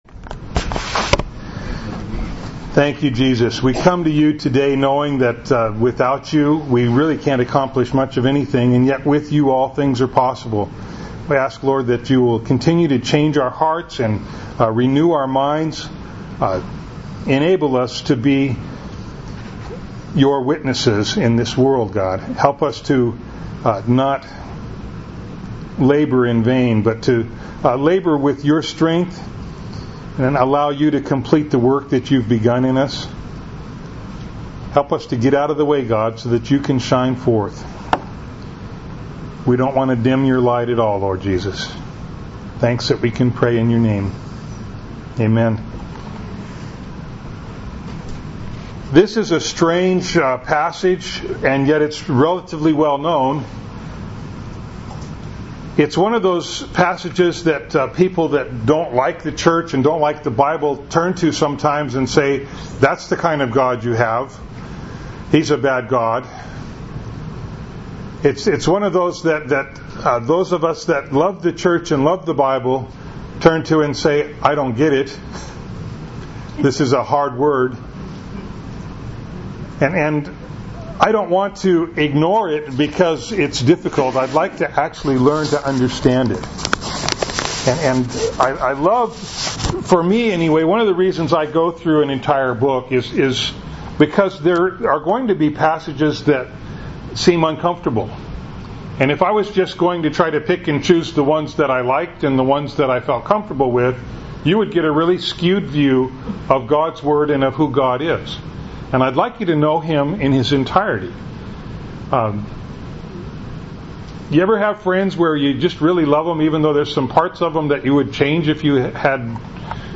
Bible Text: Genesis 22:1-19 | Preacher